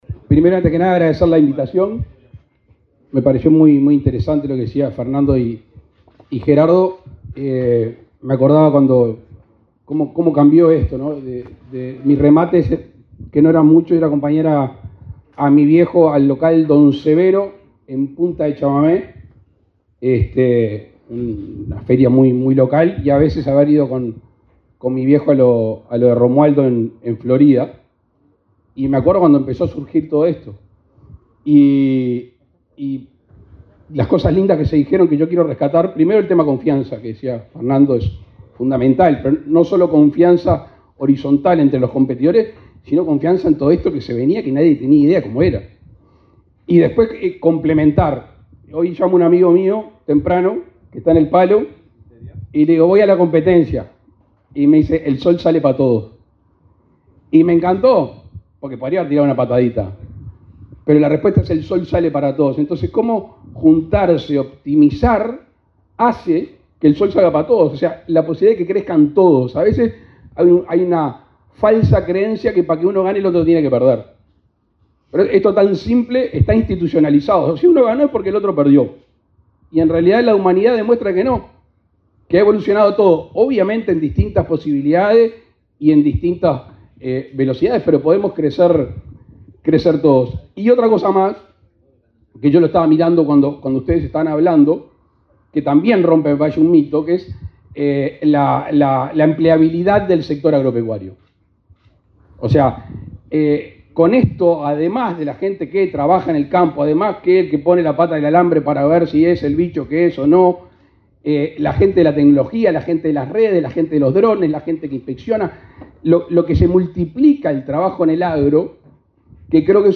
Palabras del presidente Luis Lacalle Pou
Palabras del presidente Luis Lacalle Pou 24/07/2024 Compartir Facebook X Copiar enlace WhatsApp LinkedIn Este miércoles 24 en Montevideo, el presidente de la República, Luis Lacalle Pou, participó en la ceremonia conmemorativa del 23.° aniversario de Plaza Rural, una plataforma de remates ganaderos.